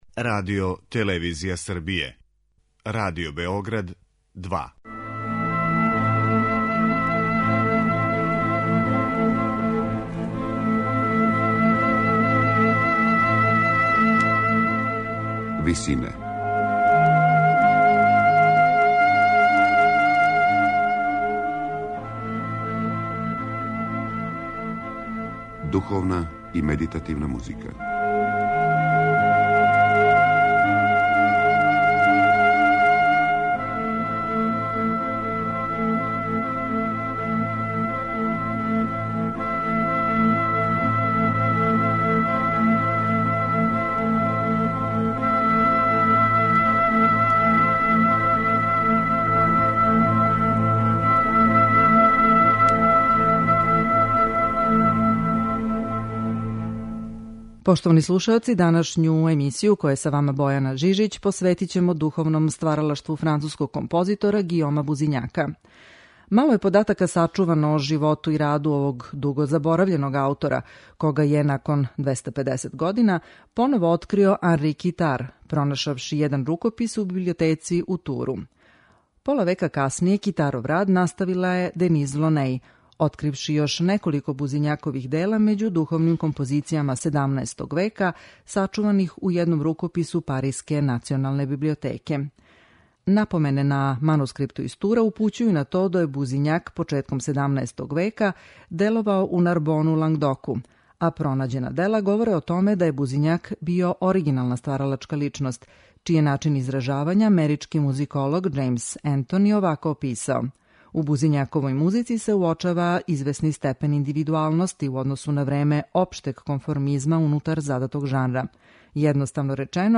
Емисија духовне музике